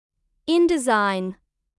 • 美式英语音标  [ə’vendʒə(r)]
• 英式英语音标  [ə’vendʒə(r)]
indesign读法
点击上面的播放按钮，即可听到正确的发音和读法，大家可以反复收听和学习，今后就不会再读错indesign这个单词了。